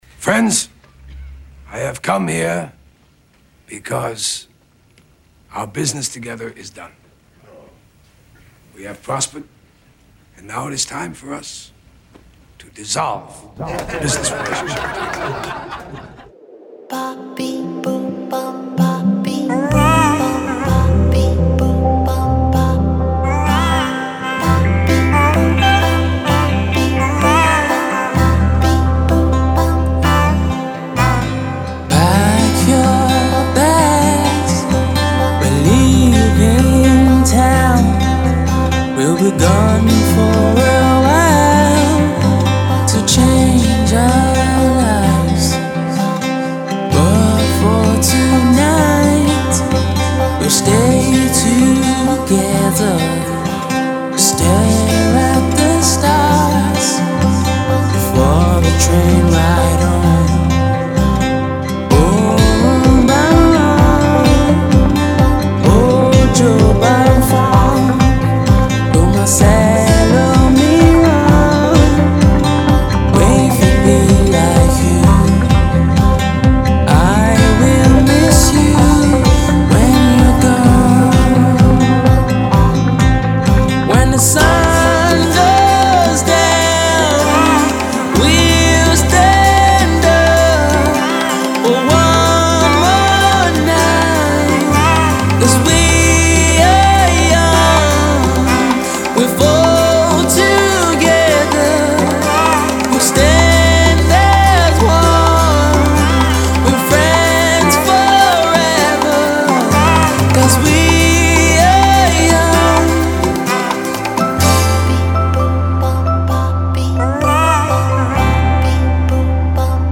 Alternative/Indie Rock